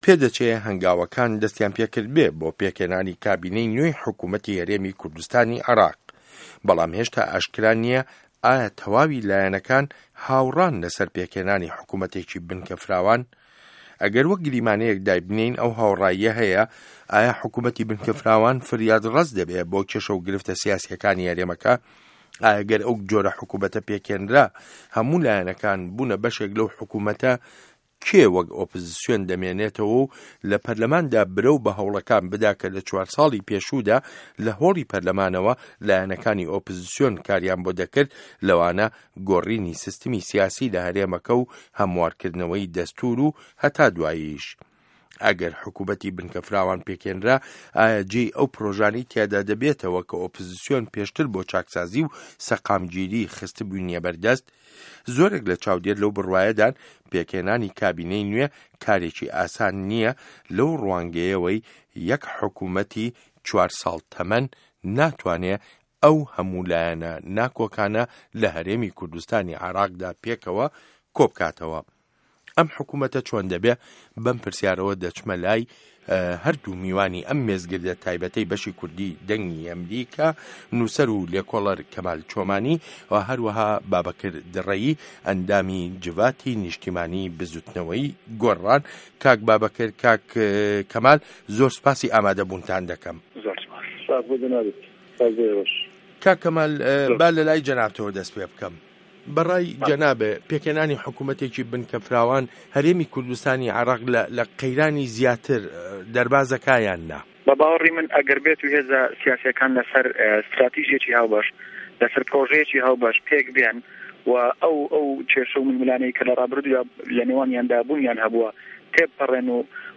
مێزگرد: پێکهێنانی حکومه‌تی بنکه‌ فراوان له‌ هه‌رێمی کوردستان